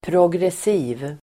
Uttal: [pr'åg:resi:v (el. -'i:v)]